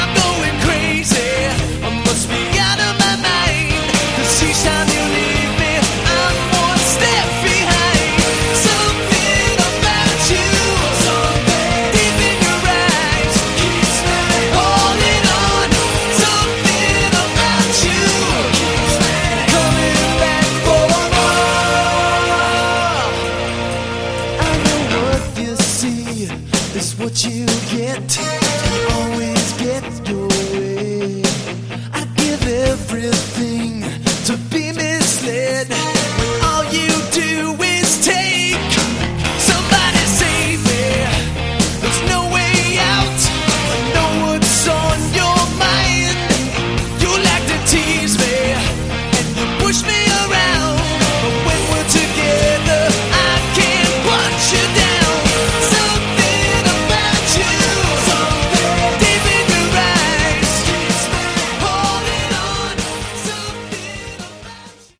Category: Hard Rock
lead vocals, lead guitar
bass guitar, background vocals
drums, keyboard Programming, background vocals
A very good melodic hard rock album.